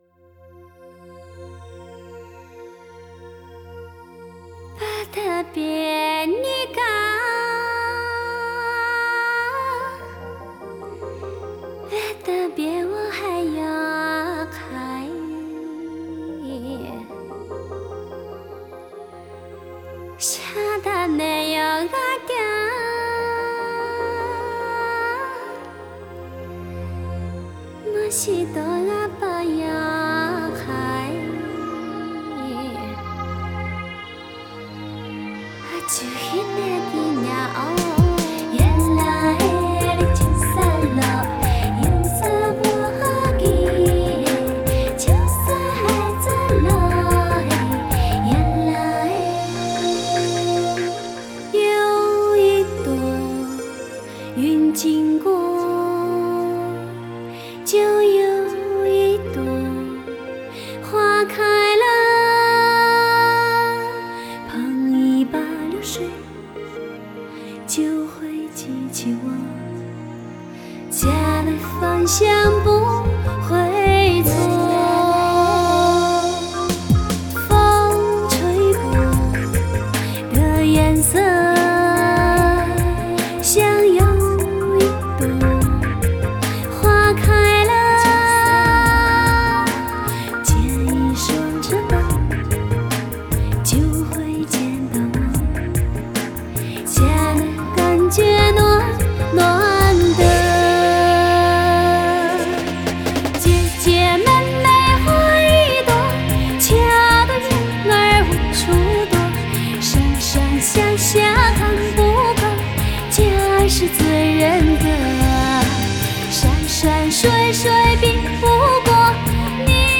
Жанр: Modern Traditional / Cinese pop / Miao folk